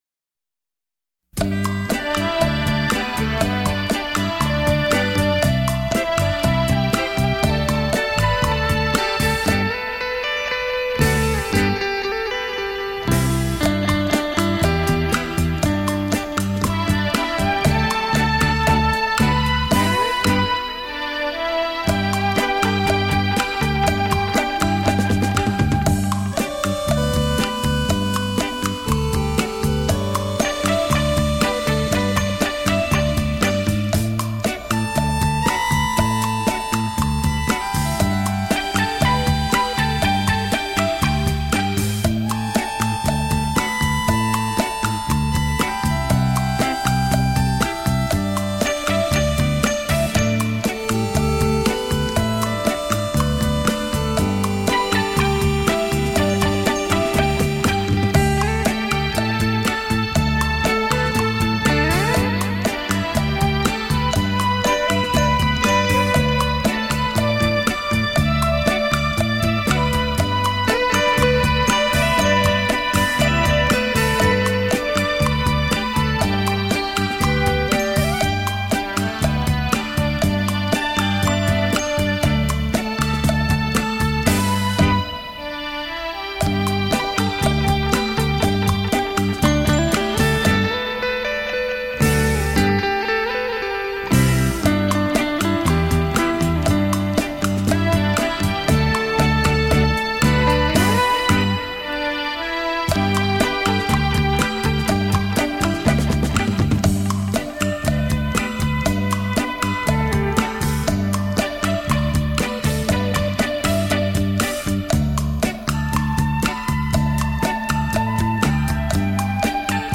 东洋音乐 十